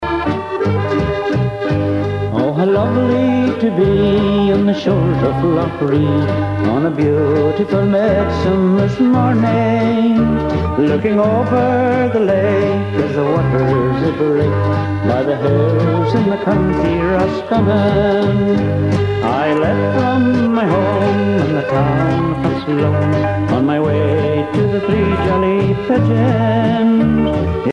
danse : valse
Pièce musicale éditée